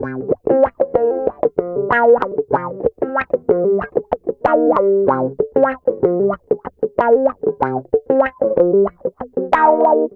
Track 13 - Clean Guitar Wah 05.wav